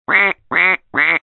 Funny Duck 3 Sound Effect Free Download
Funny Duck 3